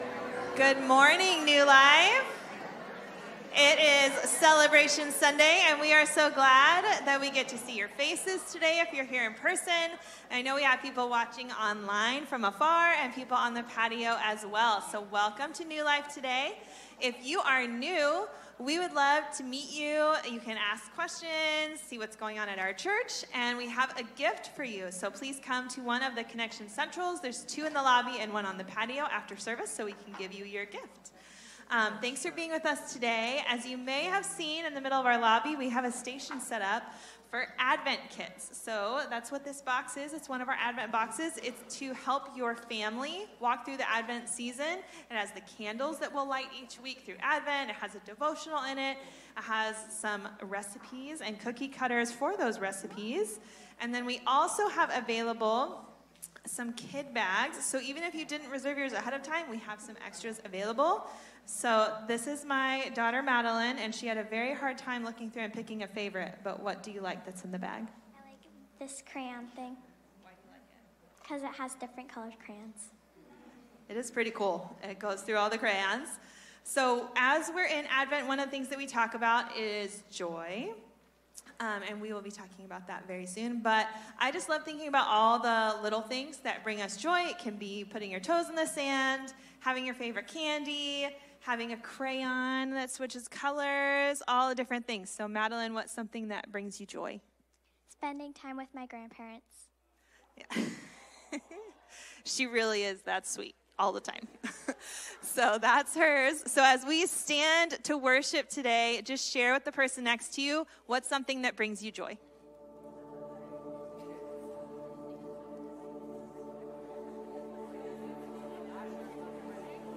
Celebration Sunday- new members, child dedications, baptisms